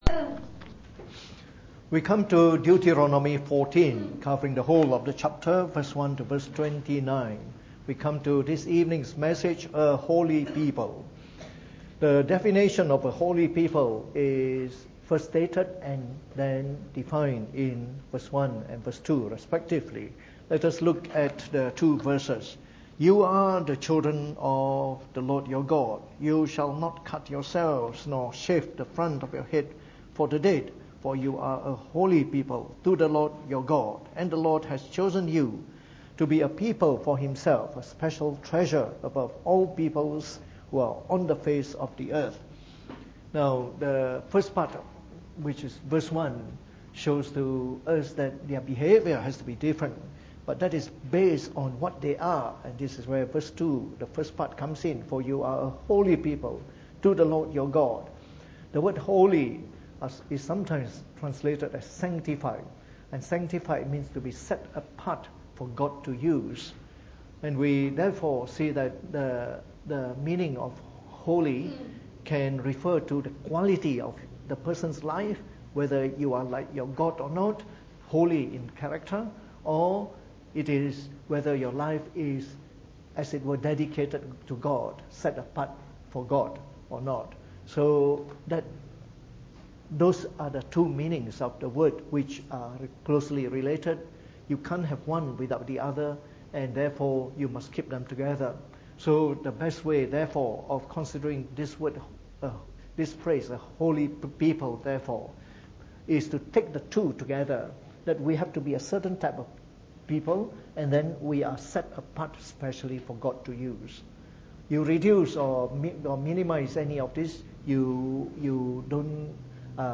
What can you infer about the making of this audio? Preached on the 2nd of May 2018 during the Bible Study, from our series on the book of Deuteronomy.